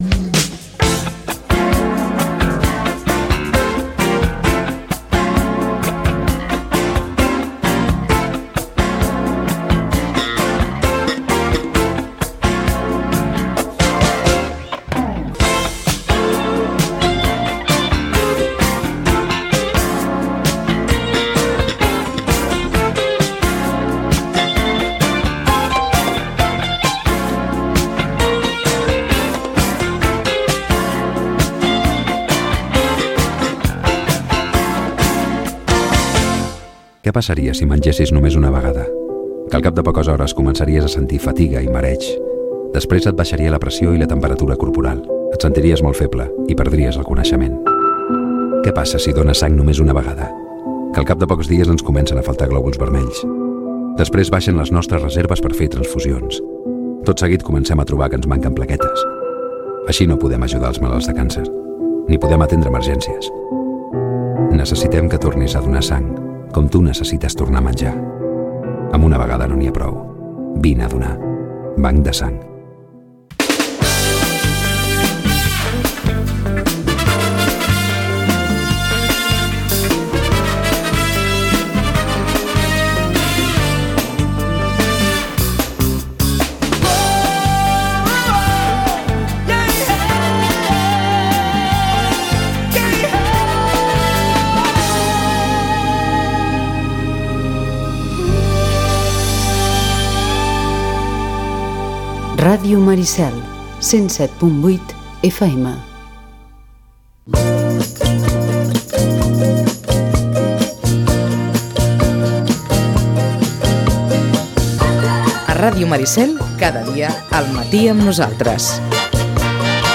Tertulia